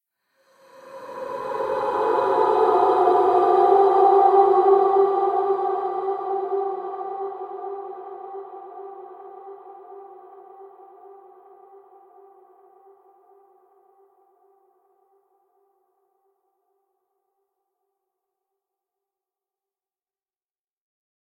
Эхо и голоса в призрачном доме
eho_i_golosa_v_prizrachnom_dome_pxx.mp3